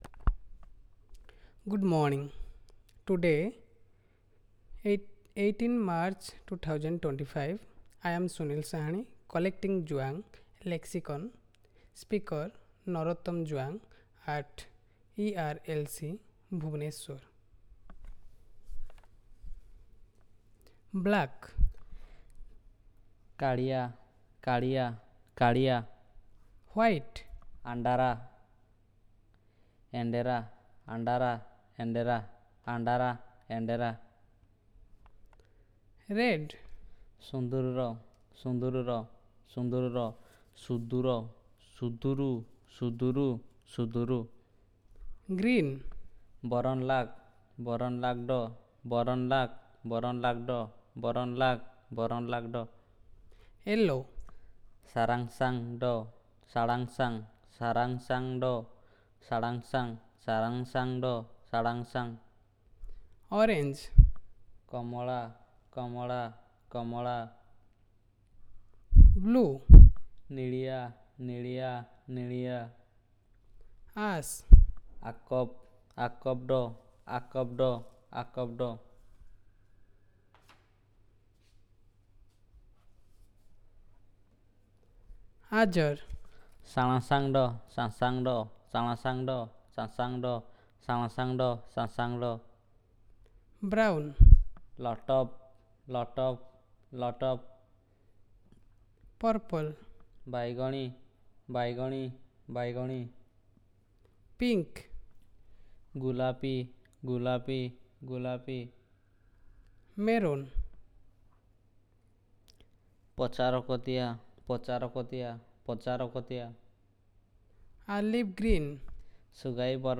Elicitation of Colour terms
NotesThis section features color terms in Juang, highlighting how the language describes visual perception. The audio examples present each color word in isolation and within simple phrases.